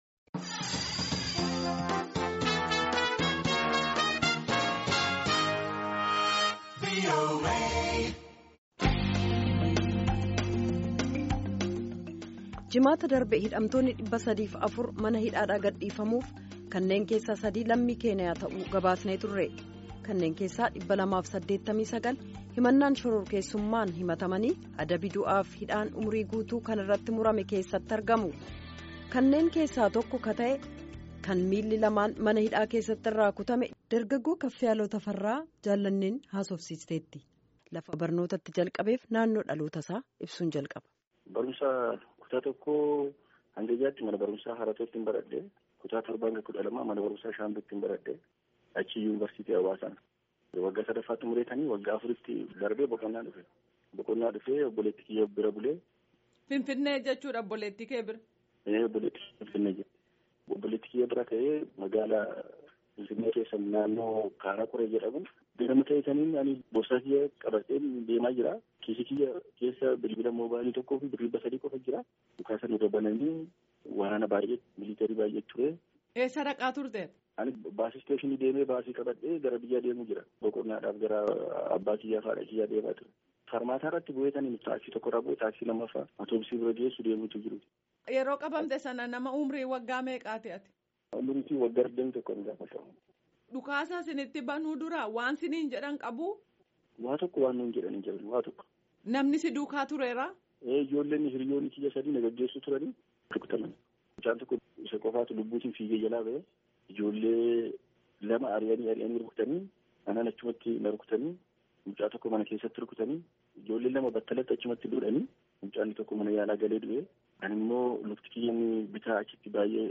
Gaaffii fi deebii gaggeeffame caqasaa.